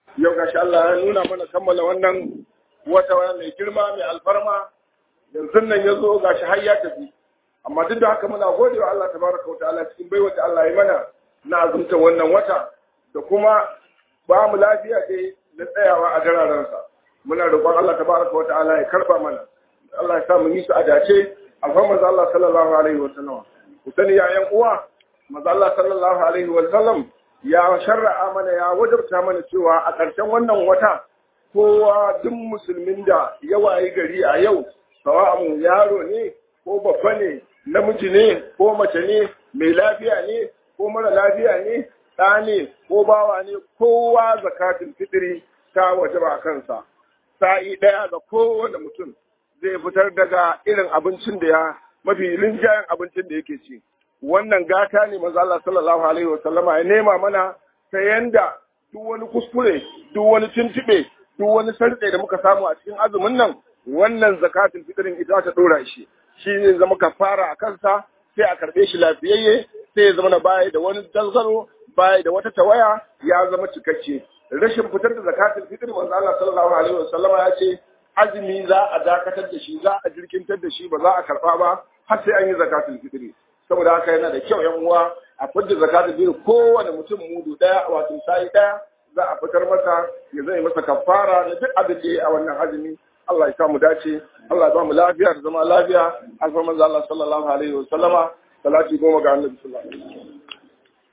HUDUBAR IDIL FIDIR 2023 2.mp3